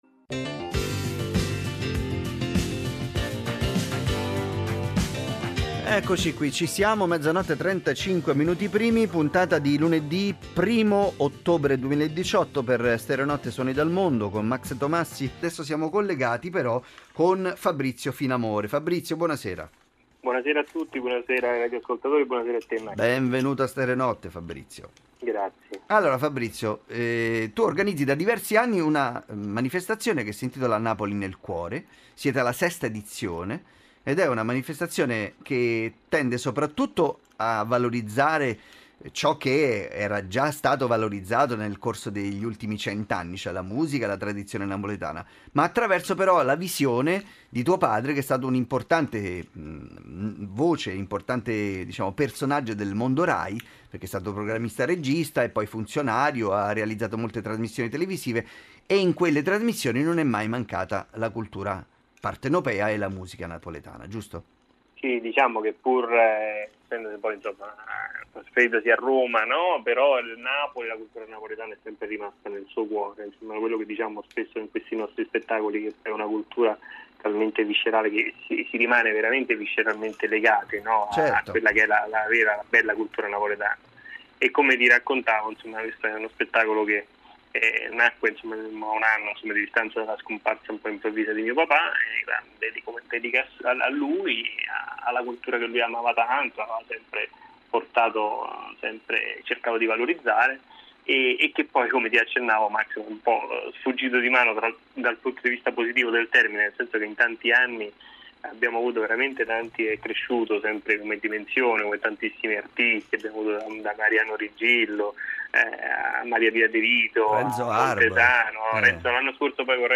Intervista Radio Uno Rai Stereonotte